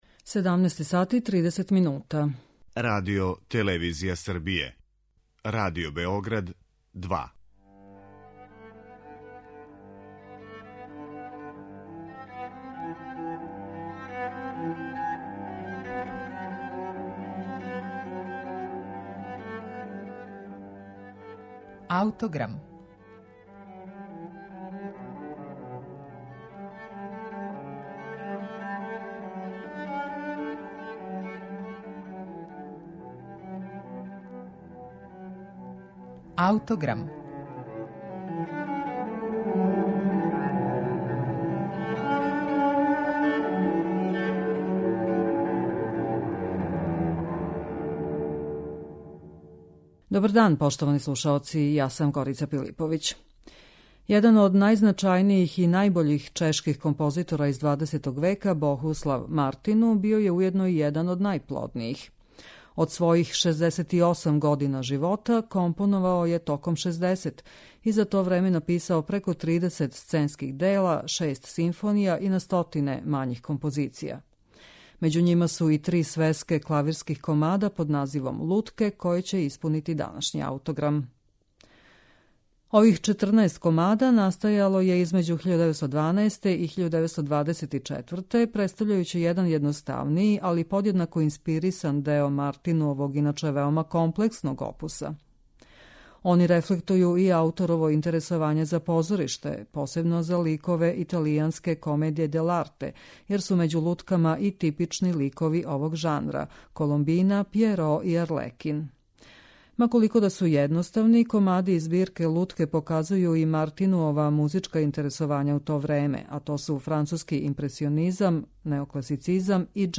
комада за клавир